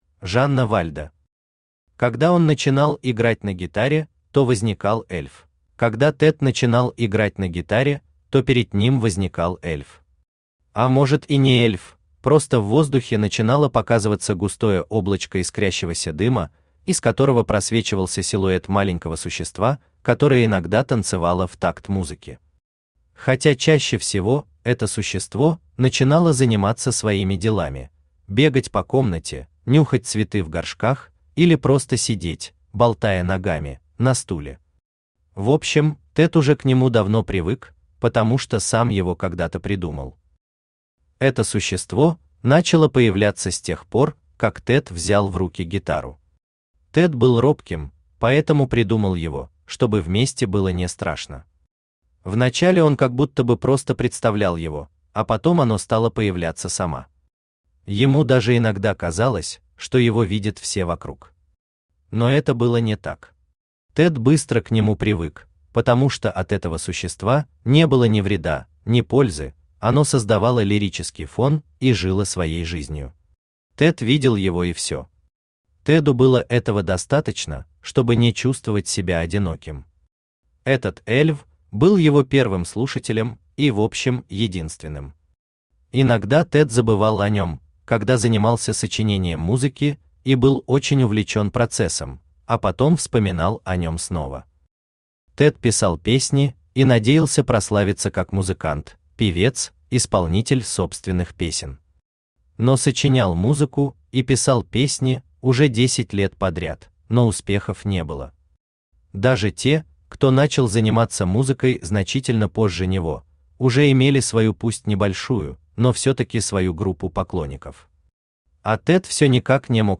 Аудиокнига Когда он начинал играть на гитаре, то возникал эльф | Библиотека аудиокниг
Aудиокнига Когда он начинал играть на гитаре, то возникал эльф Автор Жанна Вальда Читает аудиокнигу Авточтец ЛитРес.